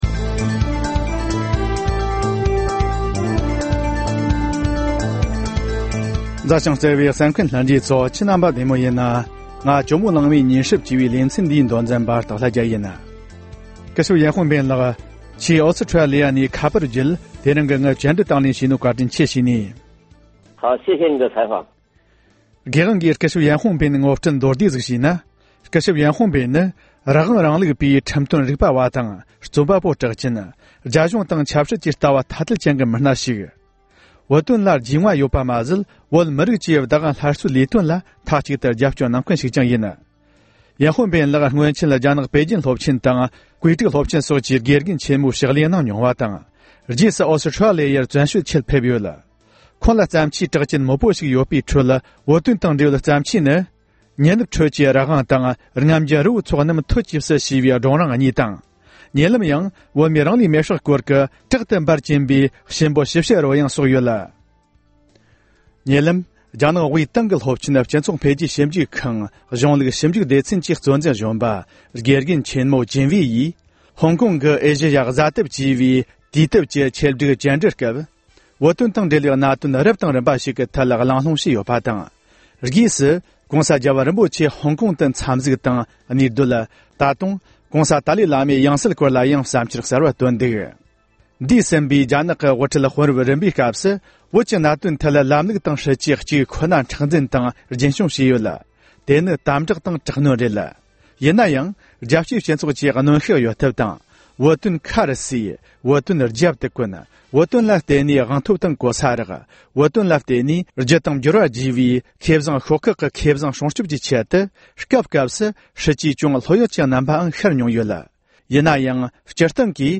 སྒྲ་ལྡན་གསར་འགྱུར།
བཅར་འདྲི